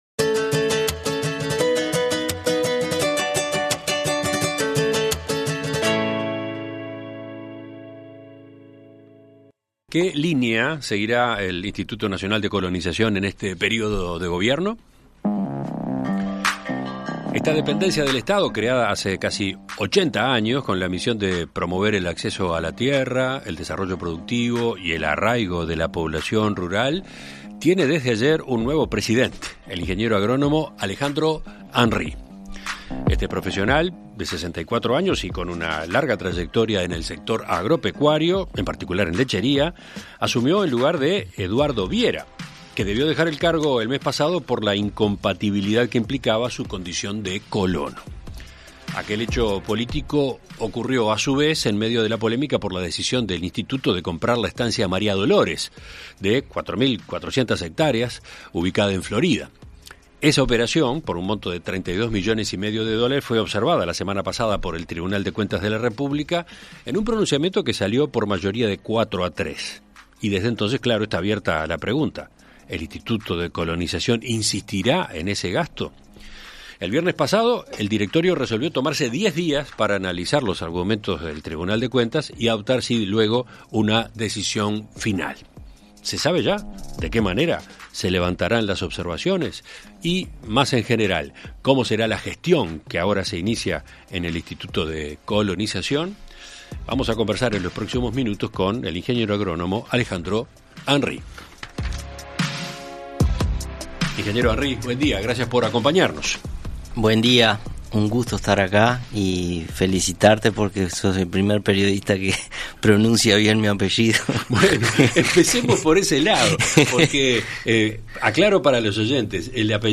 En Perspectiva Zona 1 – Entrevista Central: Rafael Radi - Océano